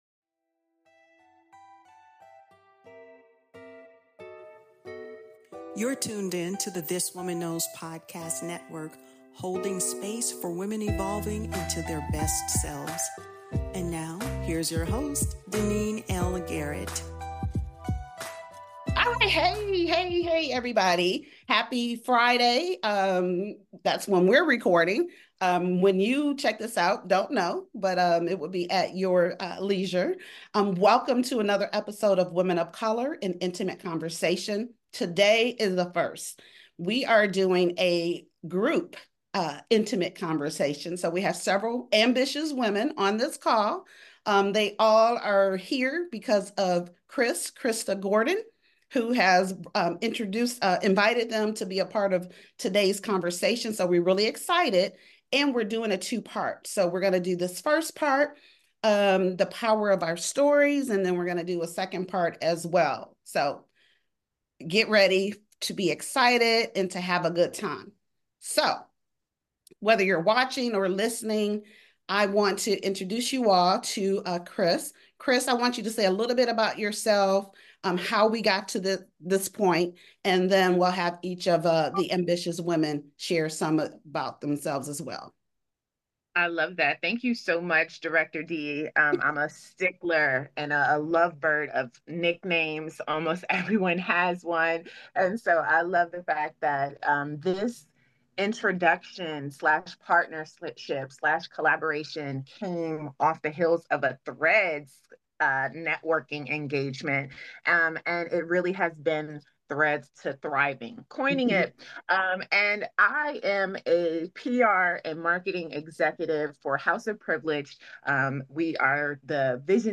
Four dynamic women of color share how their personal stories shaped their paths to leadership, legacy, and purpose. This intimate conversation explores community, empowerment, and the power of voice to transform lives—beginning with our own.